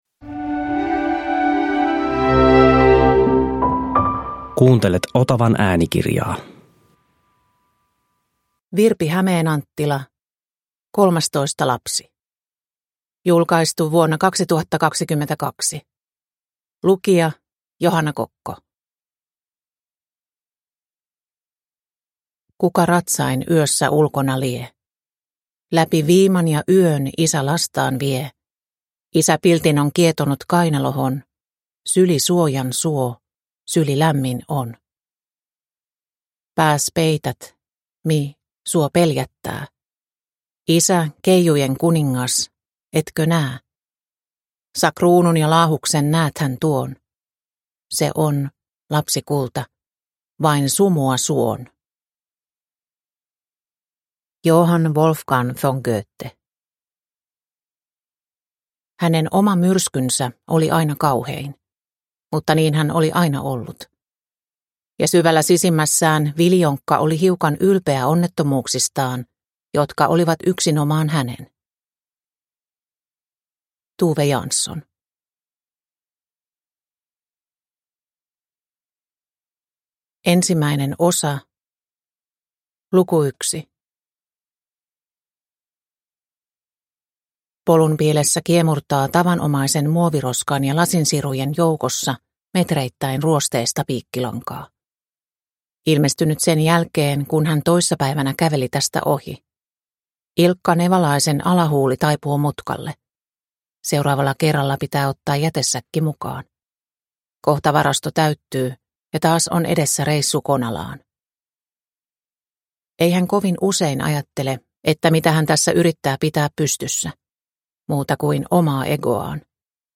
Kolmastoista lapsi – Ljudbok – Laddas ner